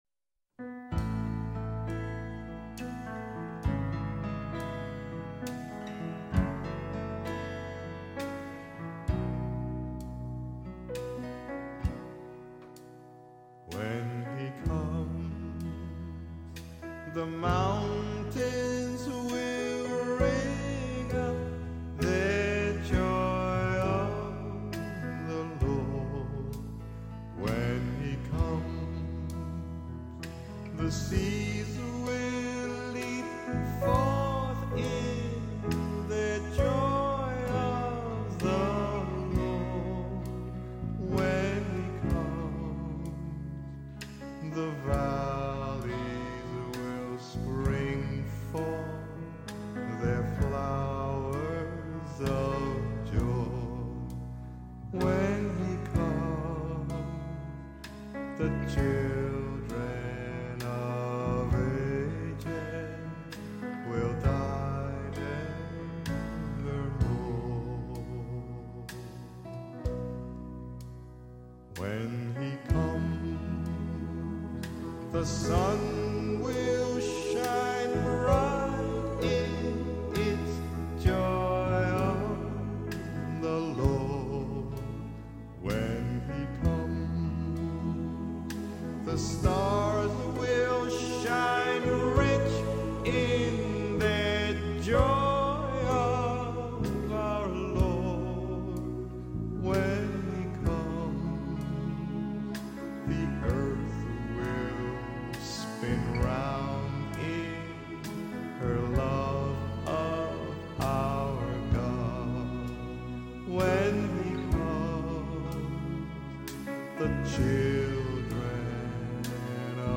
Voicing: Soloist or Soloists,SATB